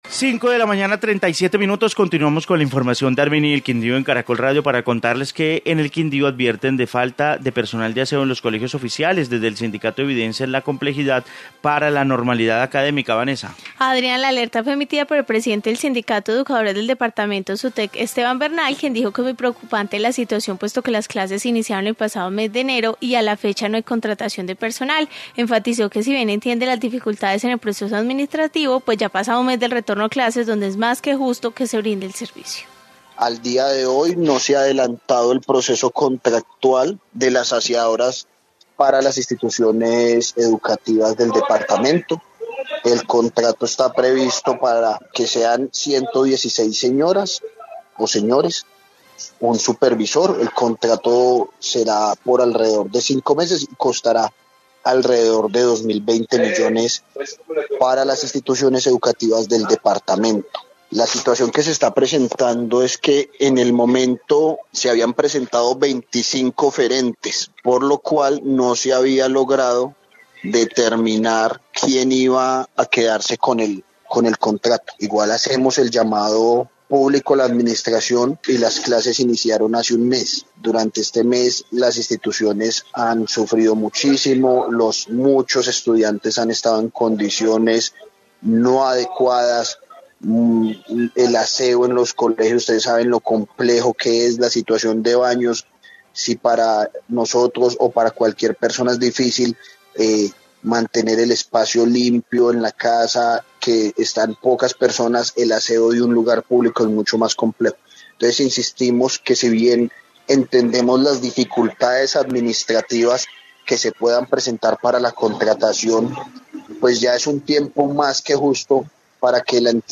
Informe educación